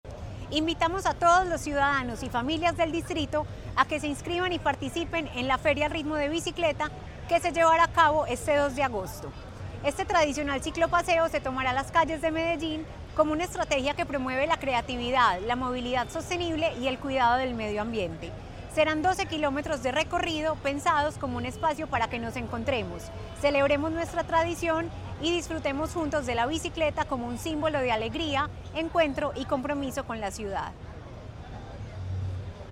Declaraciones subsecretaria de Ciudadanía Cultural, Natalia Londoño El ambiente festivo comienza a sentirse en el Distrito con la llegada de la Feria de las Flores, y una de sus actividades más esperadas ya abrió inscripciones.
Declaraciones-subsecretaria-de-Ciudadania-Cultural-Natalia-Londono-1.mp3